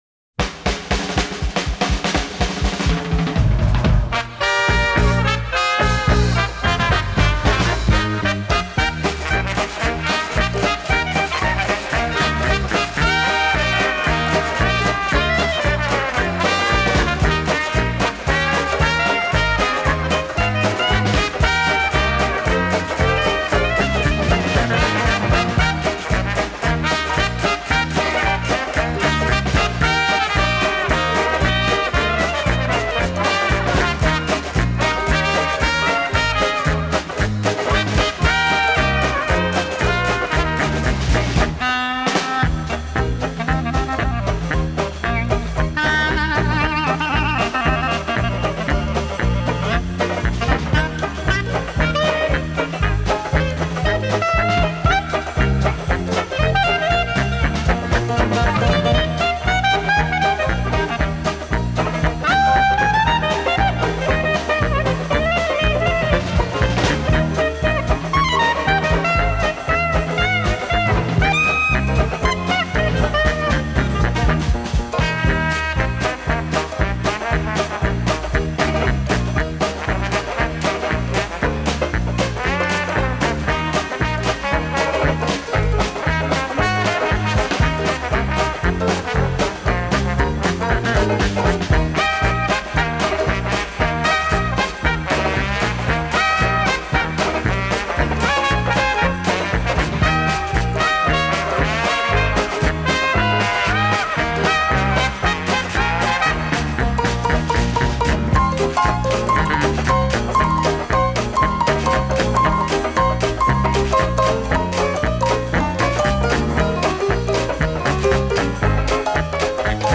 ДИКСИ 1